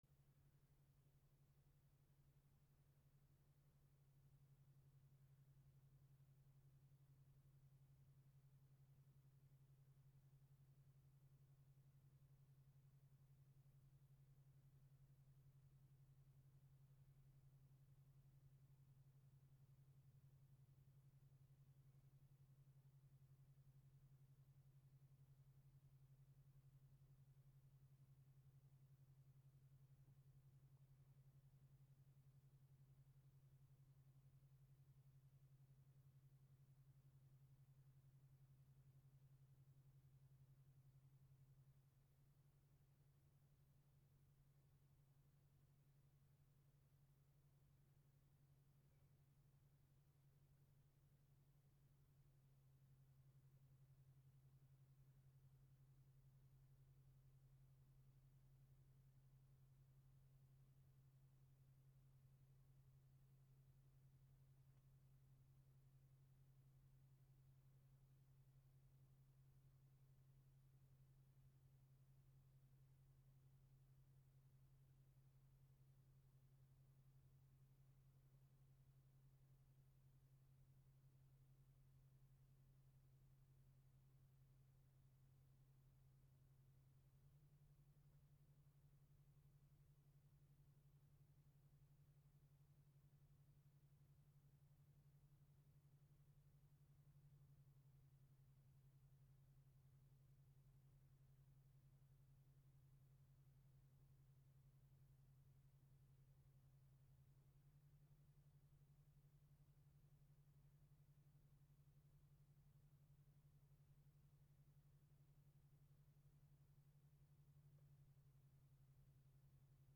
/ C｜環境音(人工) / C-25 ｜部屋、ルームトーン
ルームトーン エアコン動作